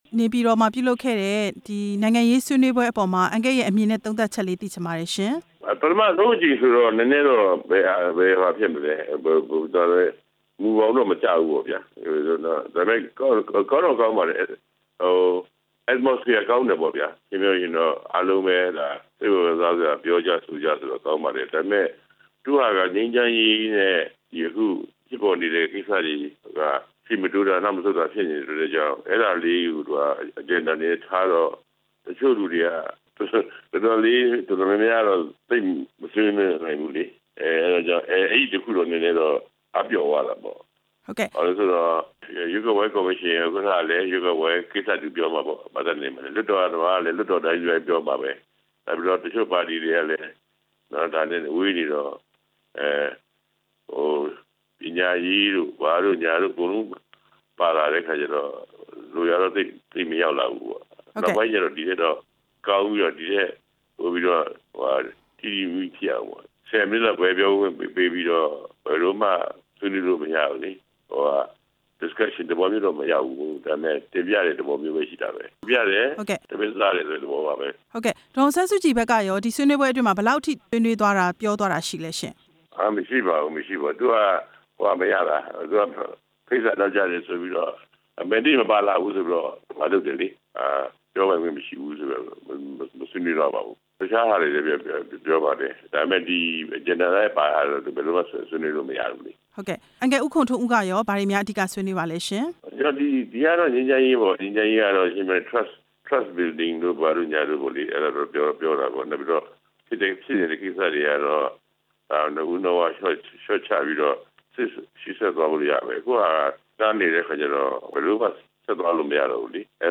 သမ္မတအိမ်တော်မှာပြုလုပ်တဲ့ နိုင်ငံရေးဆွေးနွေးပွဲအကြောင်း မေးမြန်းချက်